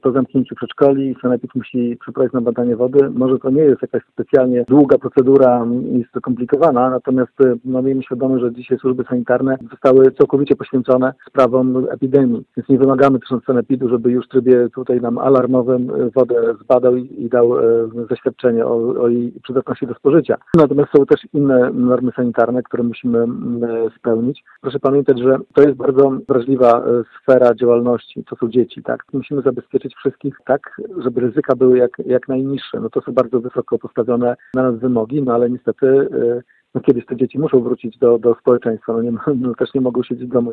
– Uruchomienie opieki przedszkolnej 6 maja jest nierealne – mówi w rozmowie z Radiem 5 burmistrz Giżycka Wojciech Karol Iwaszkiewicz.
Jak mówi burmistrz Giżycka – samorząd wciąż nie ma wyników badań jakości wody w przedszkolach.